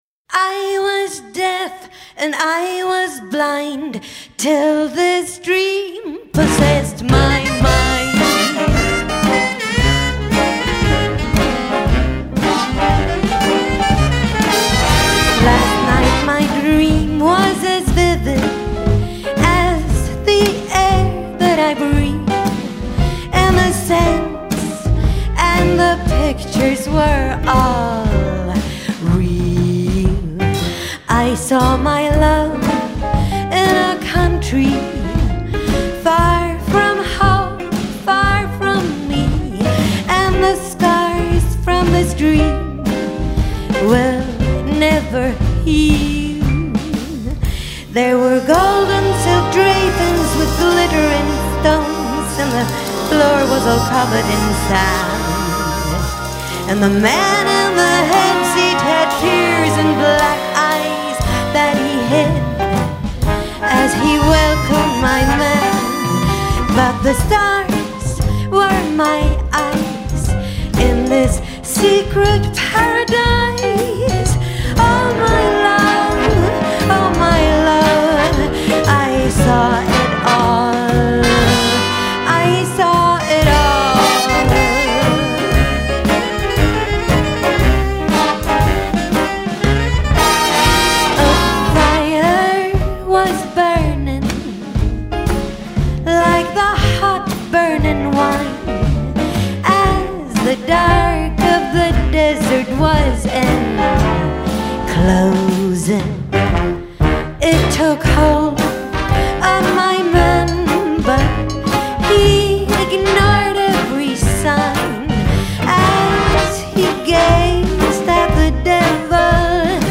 Toneart Eb
Laid-back New Orleans, 4/4 = 120
02-Desert-Song-Live.mp3